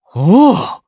Siegfried-Vox_Happy4_jp.wav